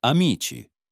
Soft C (before E, I) = "CH" as in "cheese"
friends ah-MEE-chee